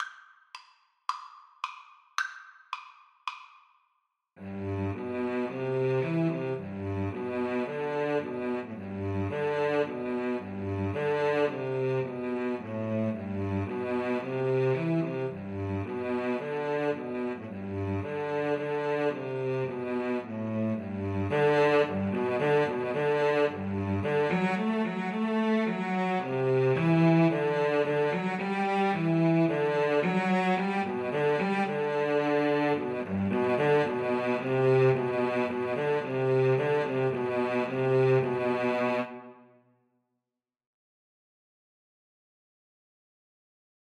Cello 1Cello 2
Allegro Moderato = c.110 (View more music marked Allegro)
4/4 (View more 4/4 Music)